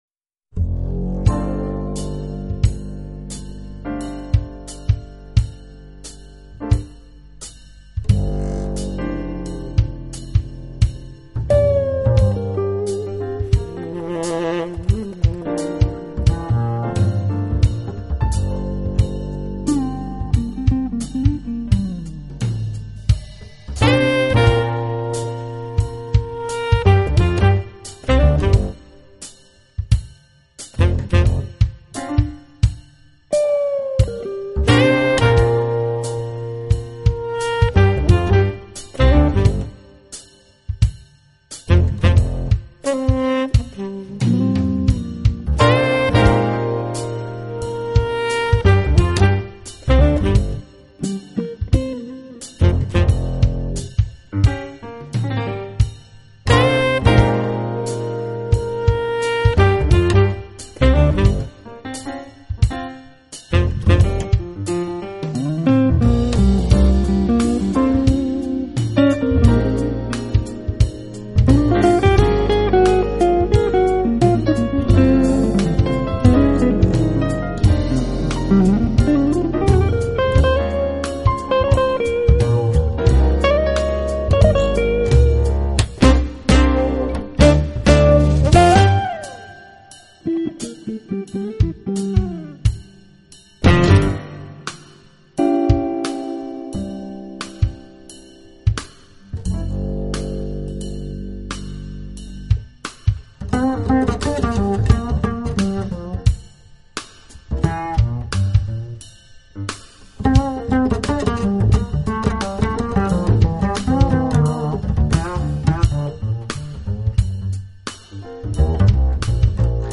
他的声音更是一绝！